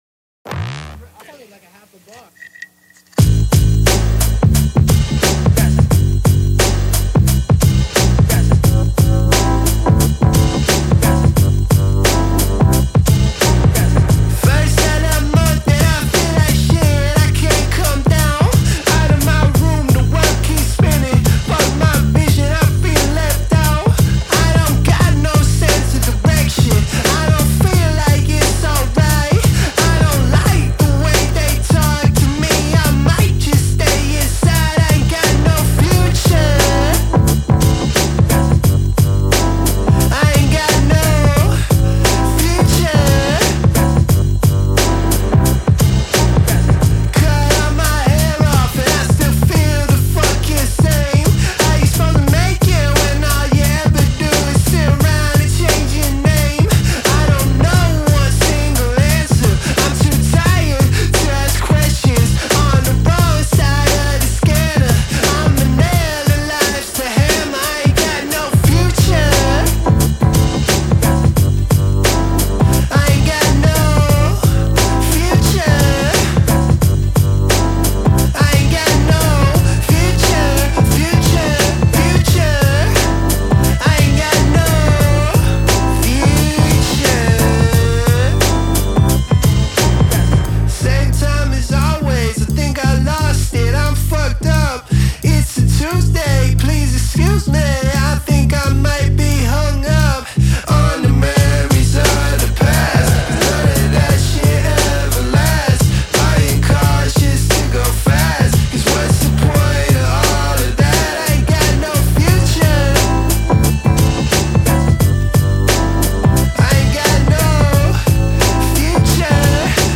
Трек размещён в разделе Зарубежная музыка / Альтернатива.